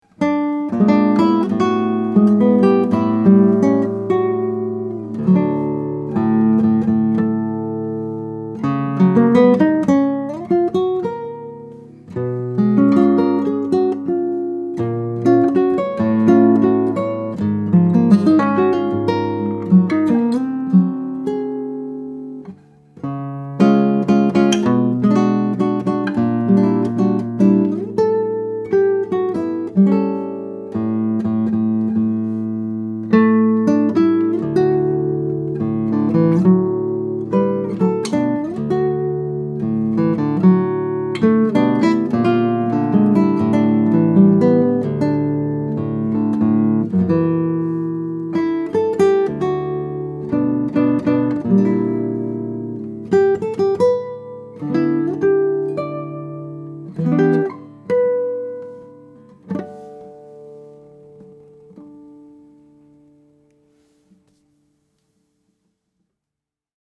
Manuel Velazquez is one of the great masters of Classical guitar building, his guitars have reached great levels of respect of players and builders alike. We have here a classical from 1968 built in German Spruce and Brazilian Rosewood that was restored by Manuel himself in 2010.